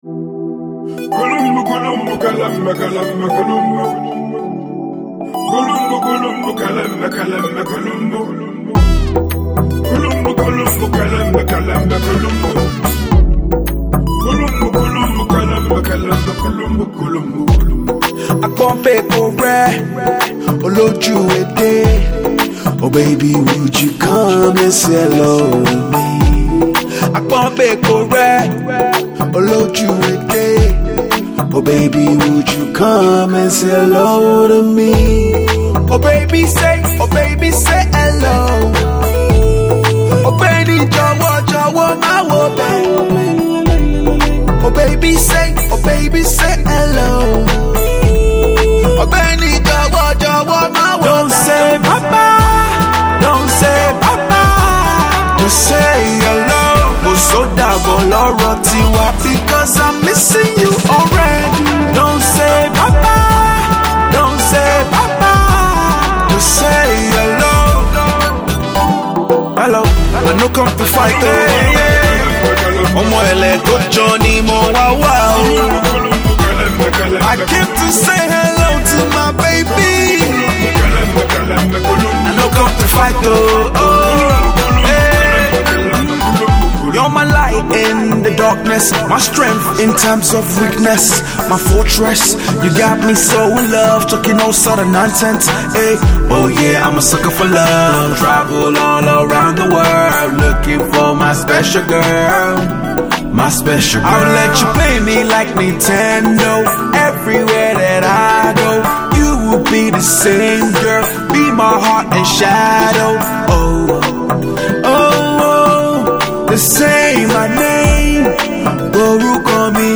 The burly soft-voiced crooner